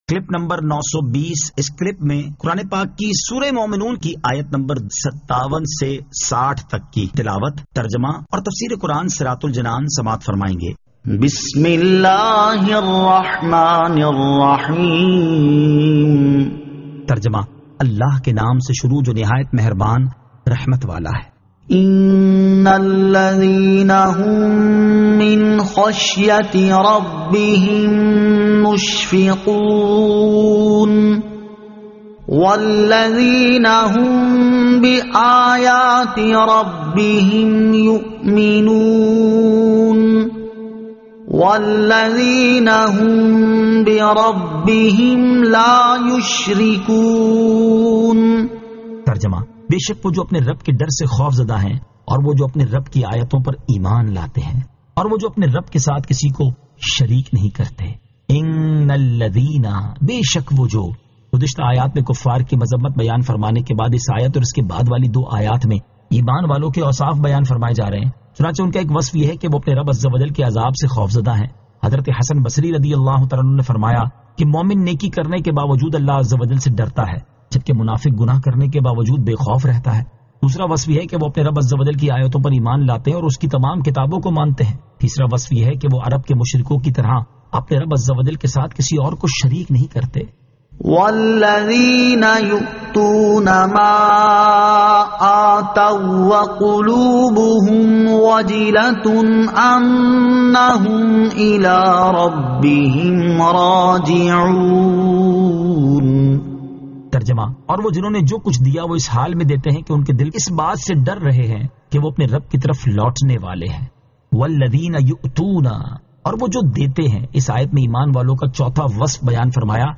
Surah Al-Mu'minun 57 To 60 Tilawat , Tarjama , Tafseer